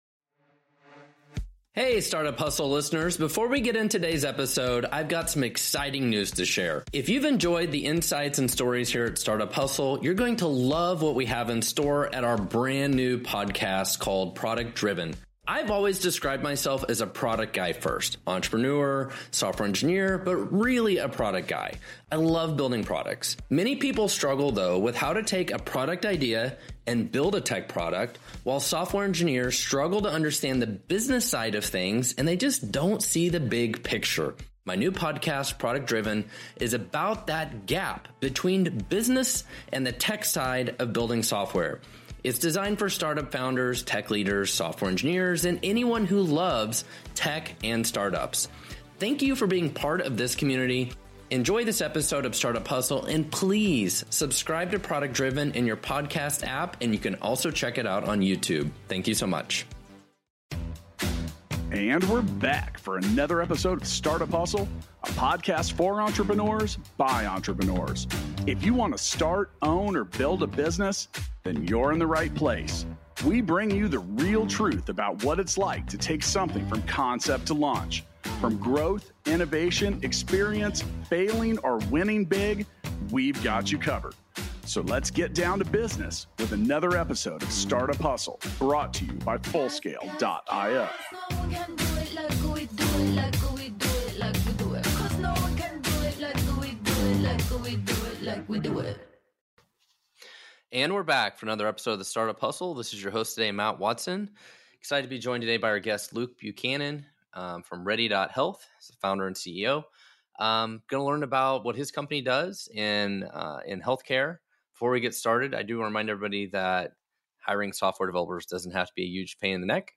for an exciting conversation about navigating the pharmaceutical industry as a startup founder. Uncover the challenges and triumphs of navigating the big pharma industry, patient support, and the nuances of pre-product fundraising for VC-backed startups.